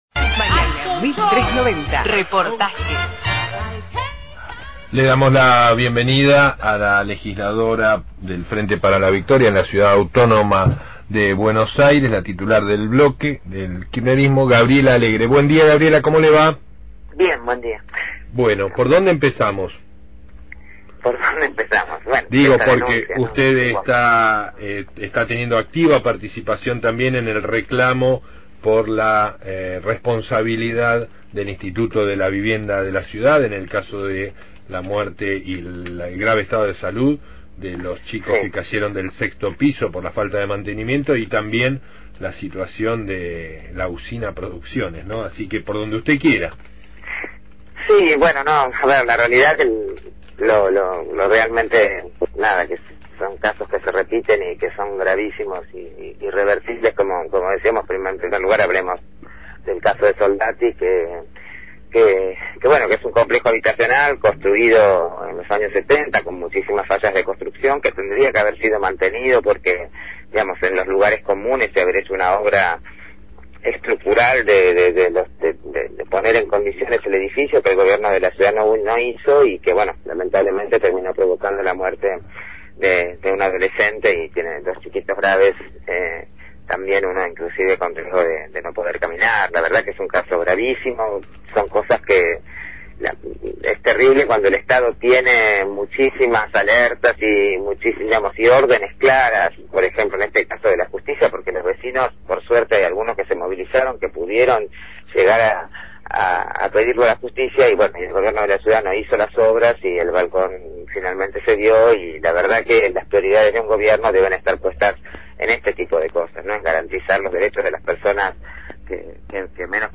Entrevita a Gabriela Alegre, presidenta del bloque del Frente para la Victoria en la Legislatura Porteña, sobre la situación del periodista Fernando Niembro ante la denuncia por corrupción en complicidad con el gobierno de la ciudad, y sobre la situación en Villa Soldati ante la falta de mantenimiento de los complejos habitacionales causa por la cual la semana pasada falleció un joven de 17 años al caer desde 24 metros de altura.